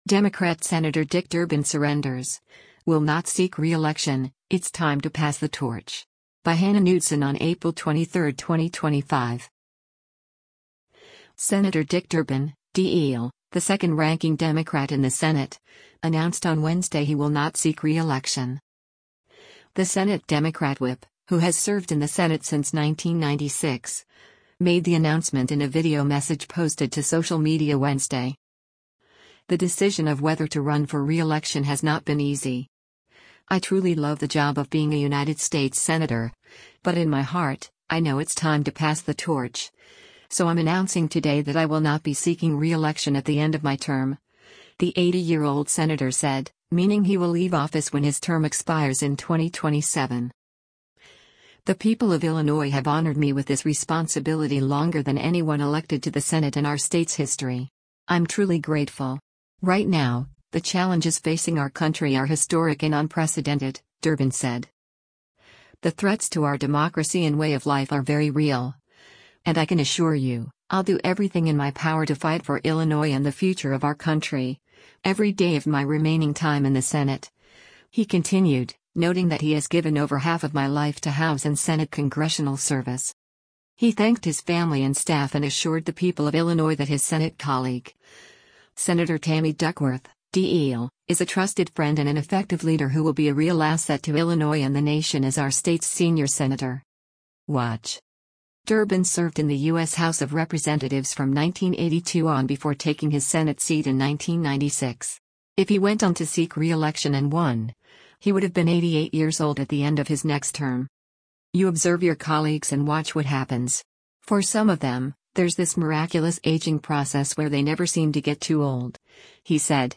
The Senate Democrat whip, who has served in the Senate since 1996, made the announcement in a video message posted to social media Wednesday.